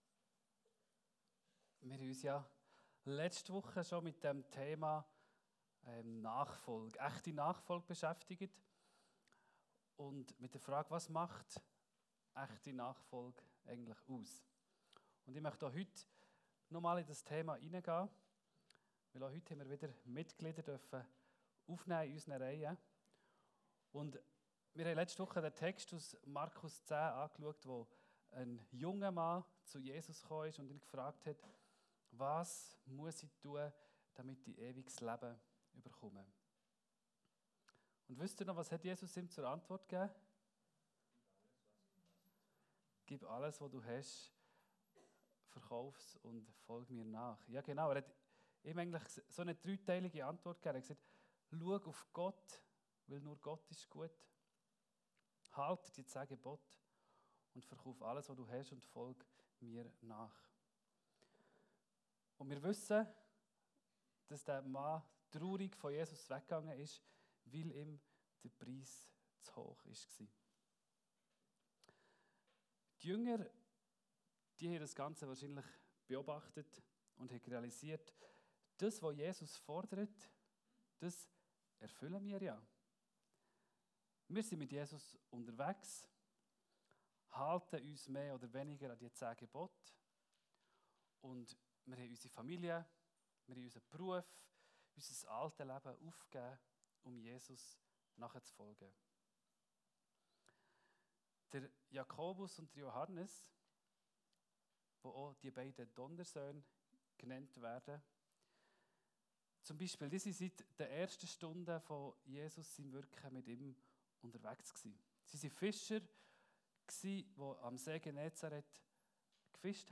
Predigt-echte-Nachfolge-2.0.mp3